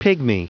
Prononciation du mot pygmy en anglais (fichier audio)
Prononciation du mot : pygmy